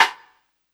Snr Clean.wav